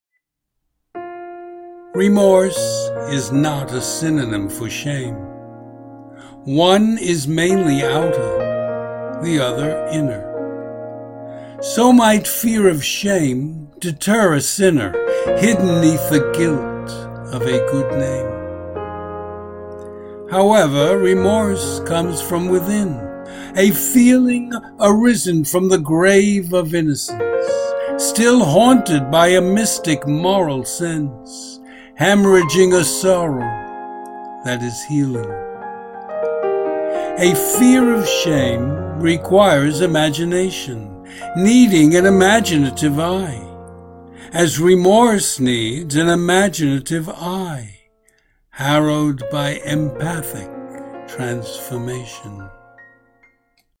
Audio and Video Music:
Nocturne.